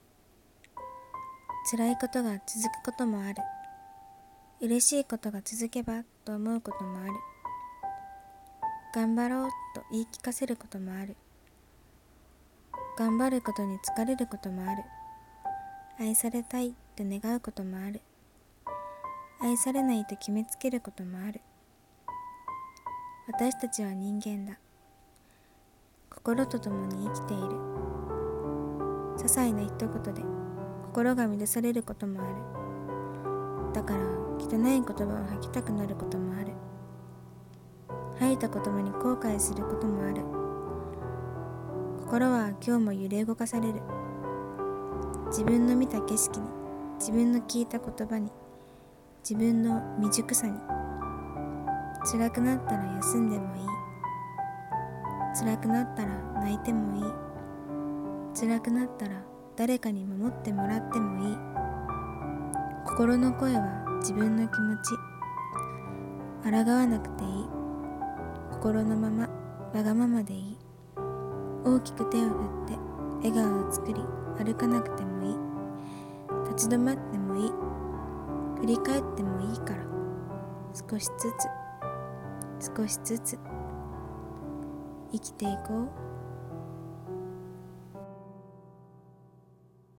声劇【少しずつ】